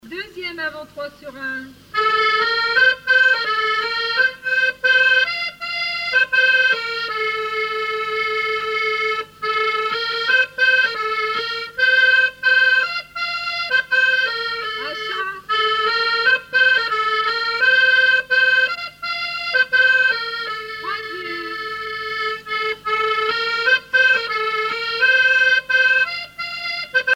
danse : quadrille : avant-trois
Musique du quadrille local
Pièce musicale inédite